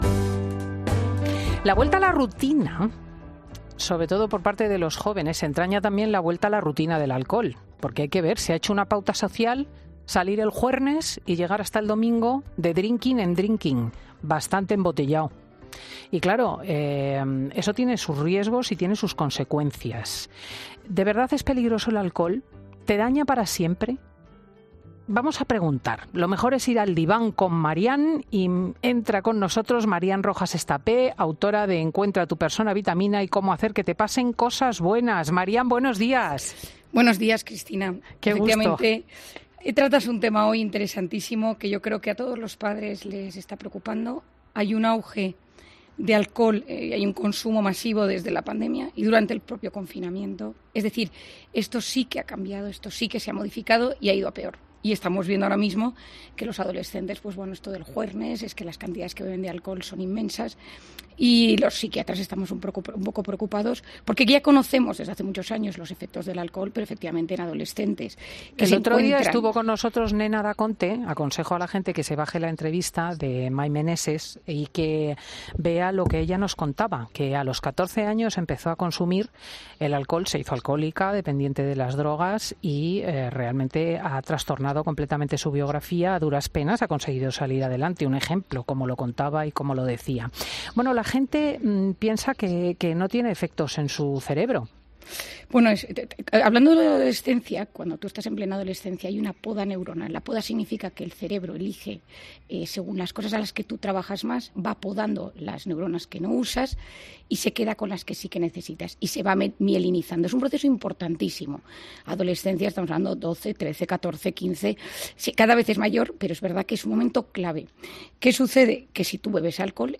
La psiquiatra Marian Rojas, analiza en Fin de Semana los problemas de la ingesta de alcohol